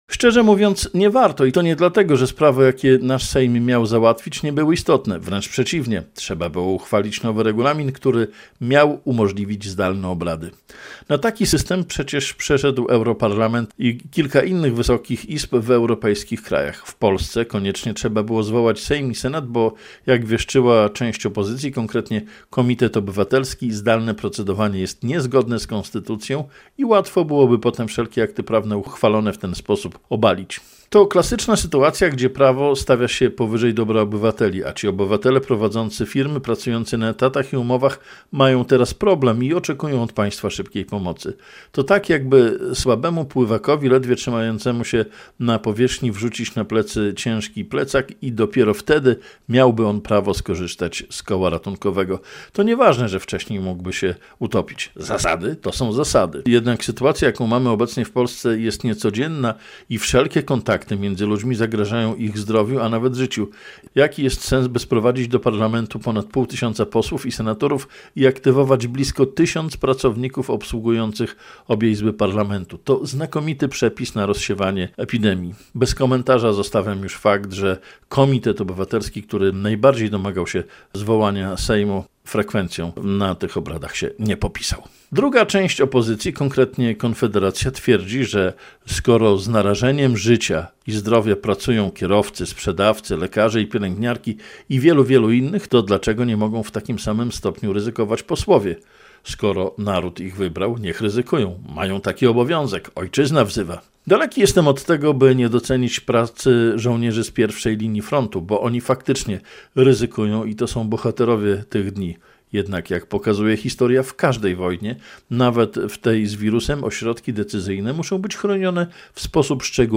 Historyczne posiedzenie Sejmu - felieton